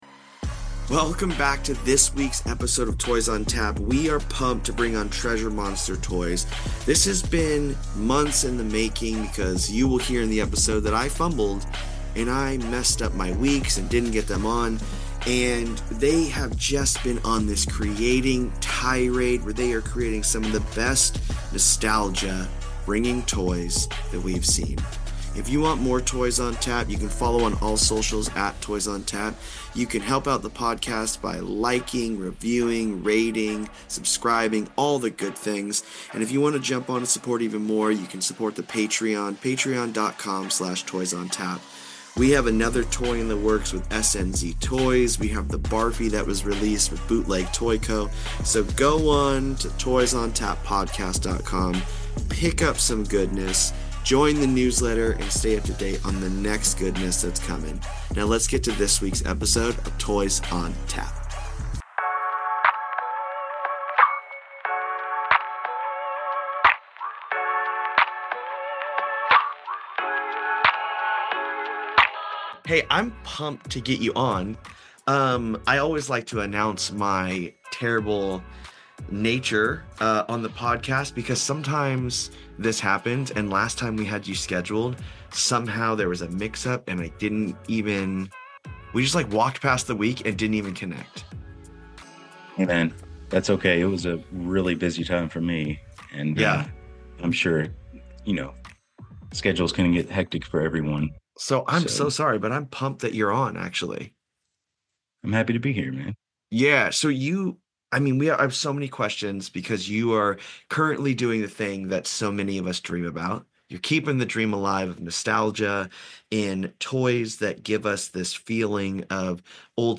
A podcast that interviews artists that make toys in the professional, independent, bootleg, and designer toy scenes!